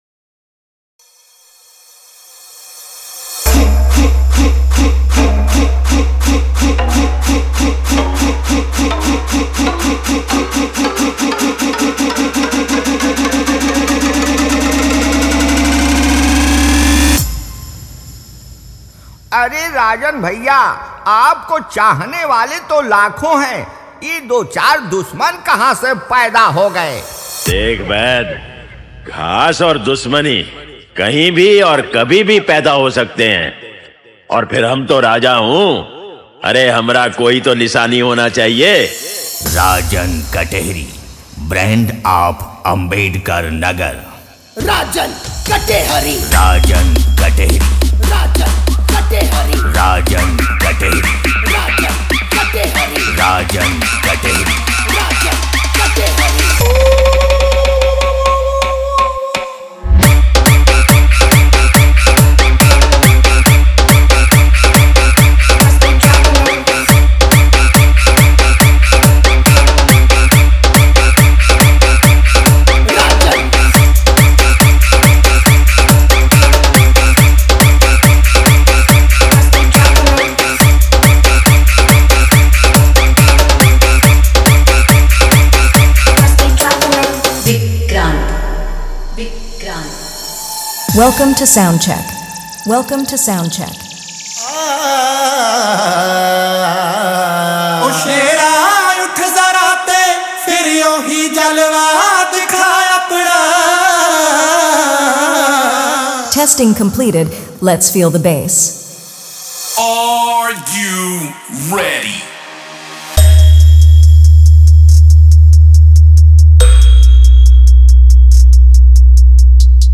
DJ Remix
DJ Competition Songs